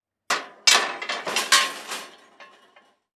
Metal_17.wav